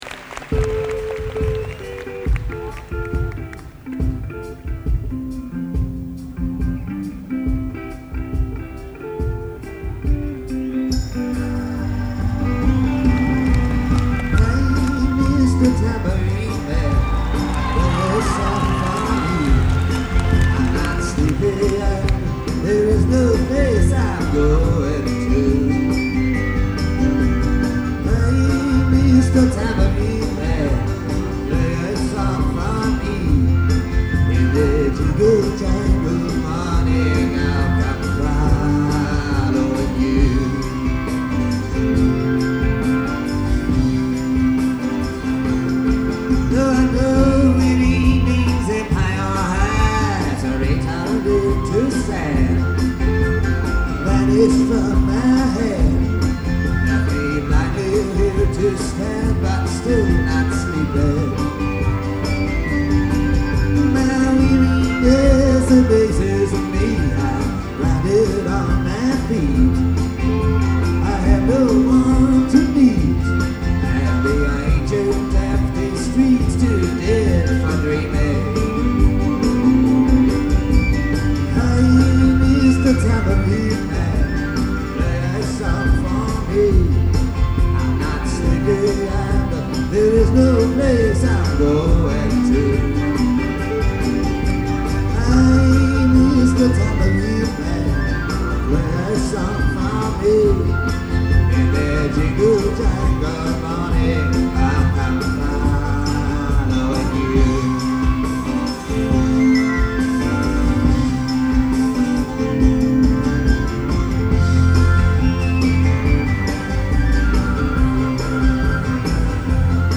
lead vocal
lead guitar
keyboards
rhythm guitar, harmony vocals
violin & mandolin
horns
bass
percussion
drums